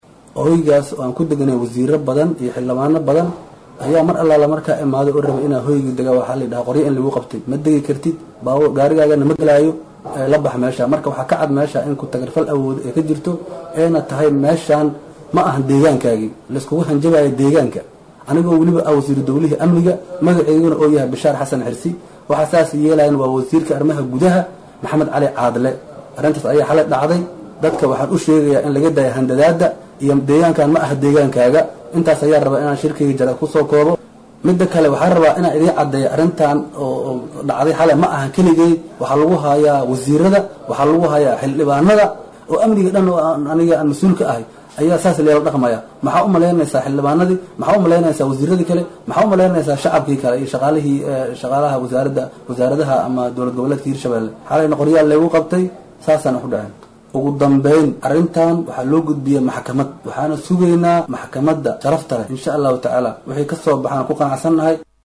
Dhageyso codka: Wasiir ka cabanaya Qoryo lagu qabtay iyo hanjabaad loo gestay | Goobsan Media Inc
Wasiir dowlaha Amniga maamulka Hirshabeelle Bishaar Xasan Xirsi oo Saxaafadda kula hadlay Magaalada Jowhar, waxaa uu sheegay in loo diiday inuu galo Hoteel uu ka deganaa Magaalada Jowhar,islamarkaana ciidamo hubeysan oo ka tirsan maamulka Hirshabeelle ay ku qabteen qoryo.